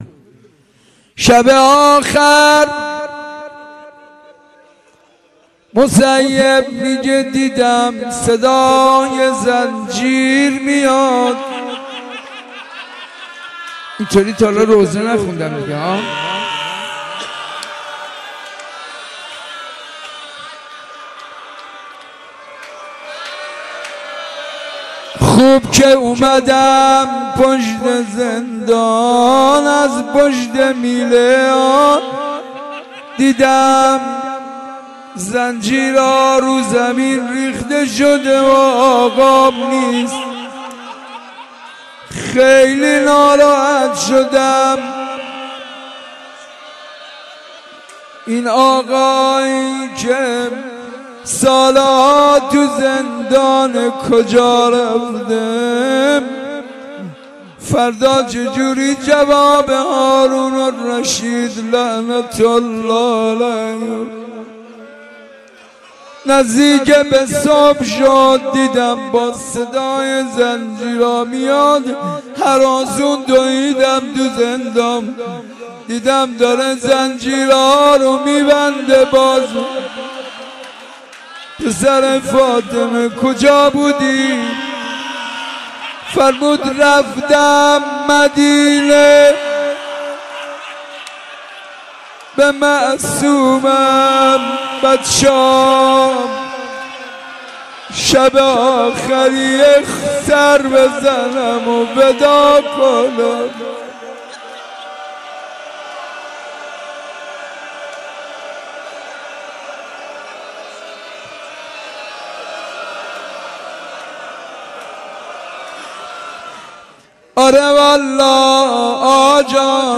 روضه ی حضرت امام موسی کاظم علیه السلام
Roze-Imam-Kazem.mp3